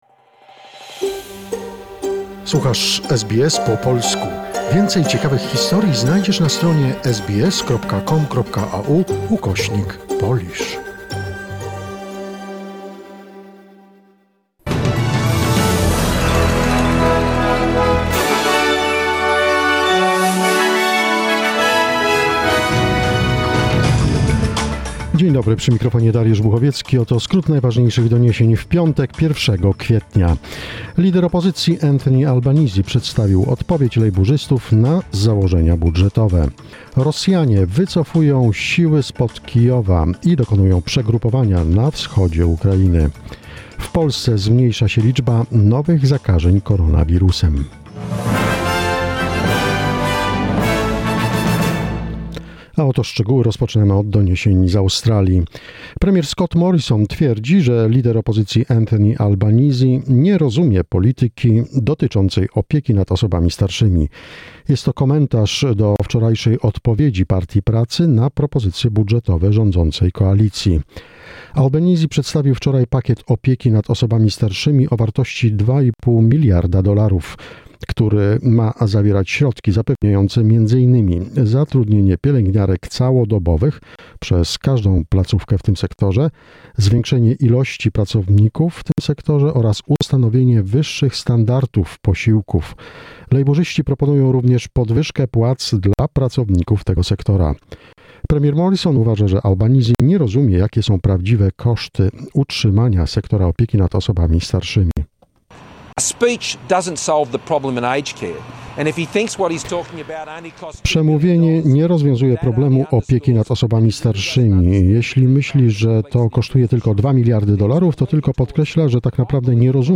SBS News in Polish, 1 April 2022